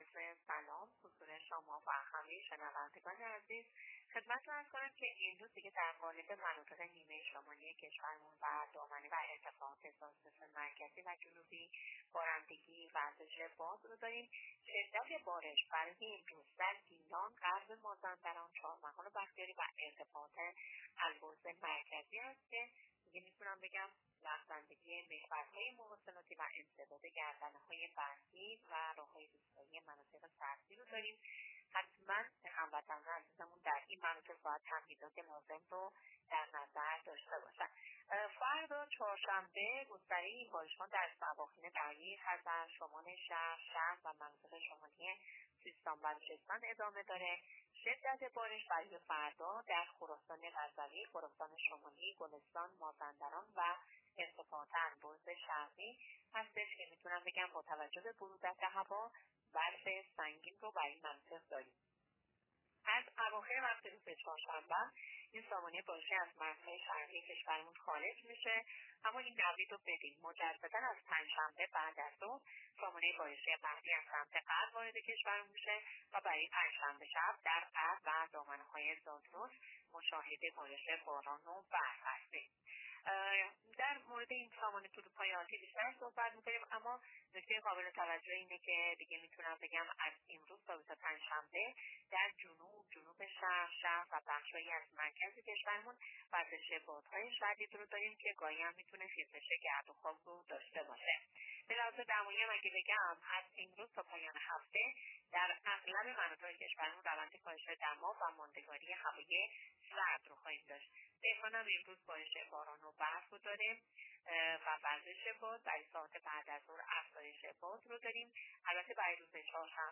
گزارش رادیو اینترنتی از آخرین وضعیت آب و هوای ۳۰ دی؛